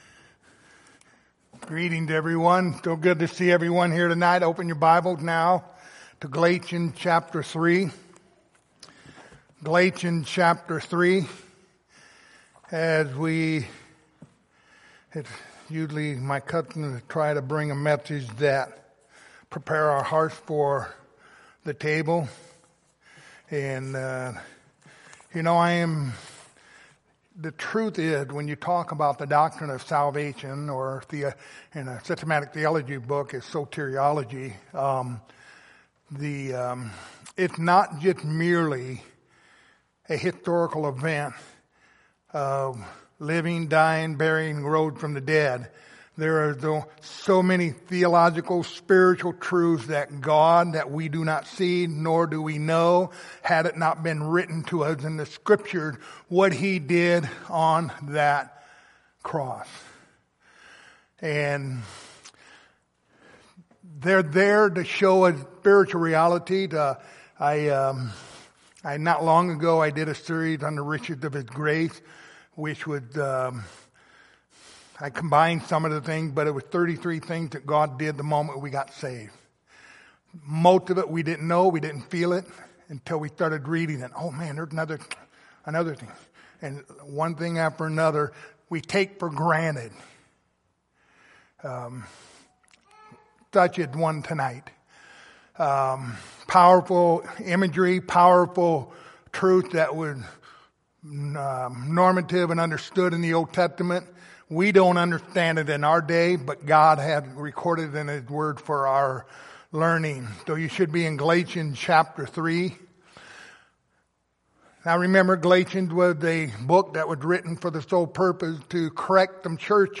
Lord's Supper Passage: Galatians 3:6-13 Service Type: Lord's Supper Topics